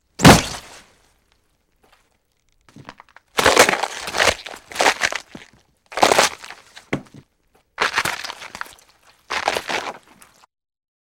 monster-sound